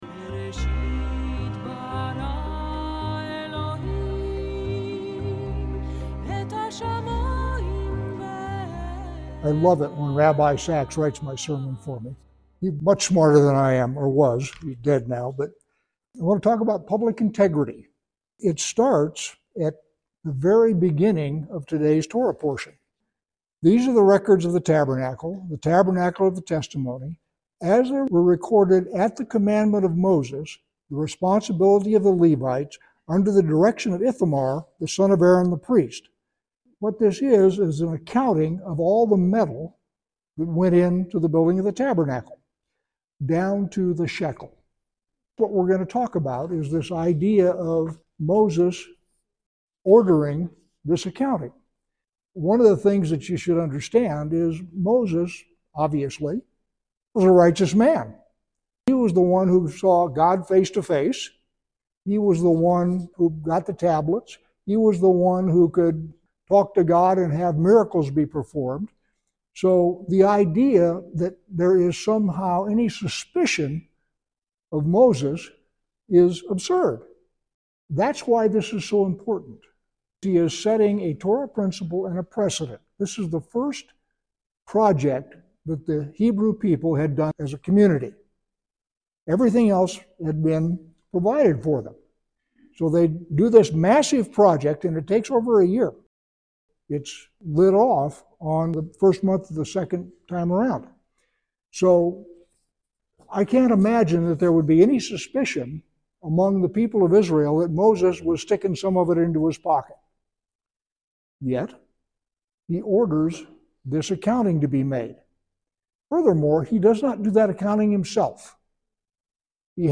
This recording is a part of the series: "Sermons 2025" You may also listen on: iTunes